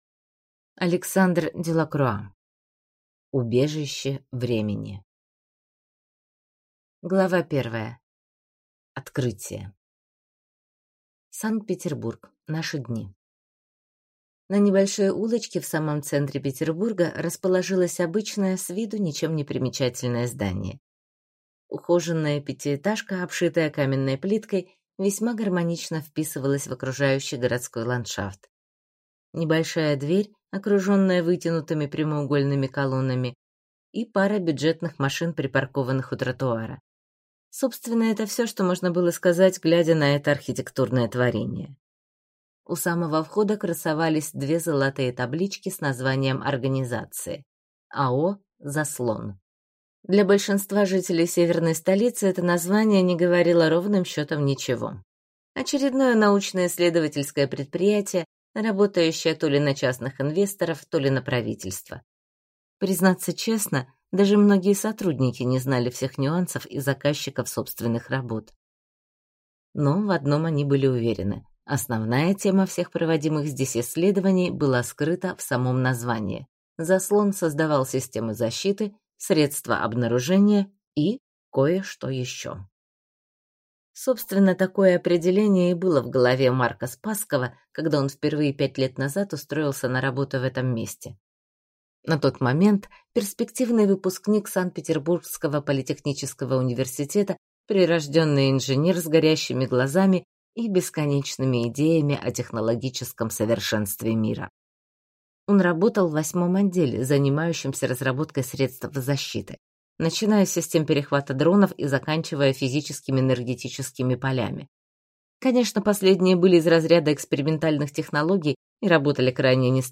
Аудиокнига Убежище времени | Библиотека аудиокниг